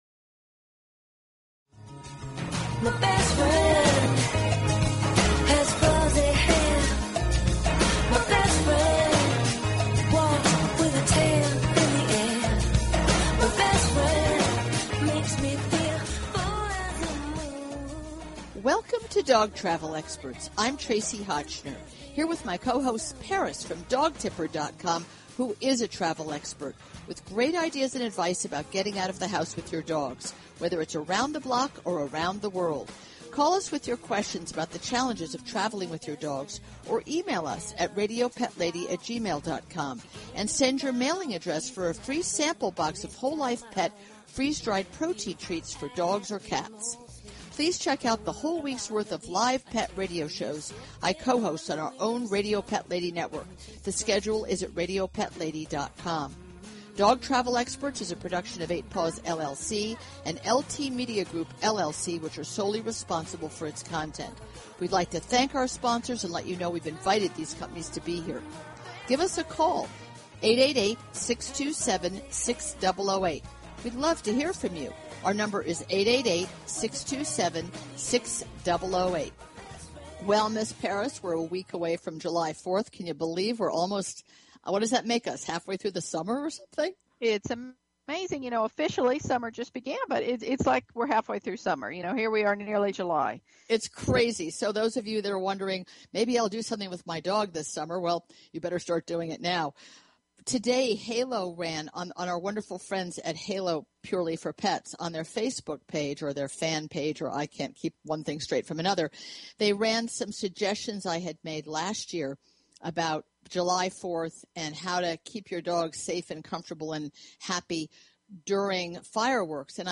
Talk Show Episode, Audio Podcast, Dog_Travel_Experts and Courtesy of BBS Radio on , show guests , about , categorized as